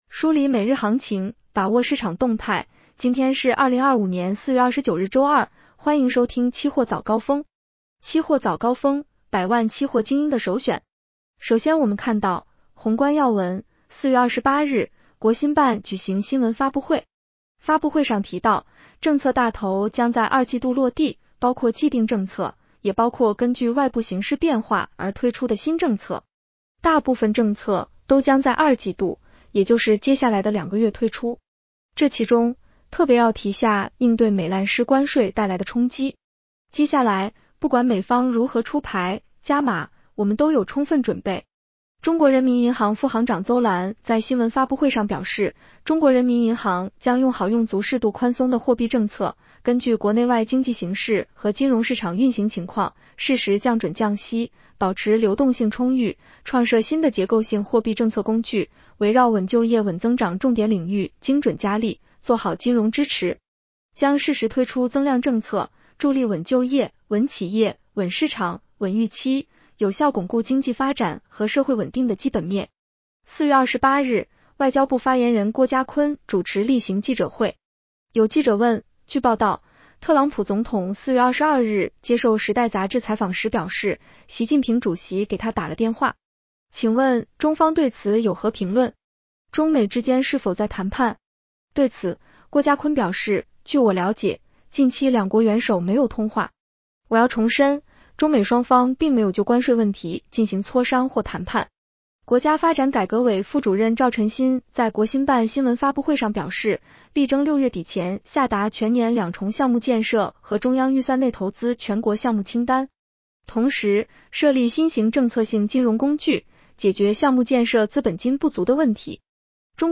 期货早高峰-音频版 女声普通话版 下载mp3 宏观要闻 1. 4月28日，国新办举行新闻发布会。